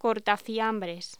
Locución: Cortafiambres
voz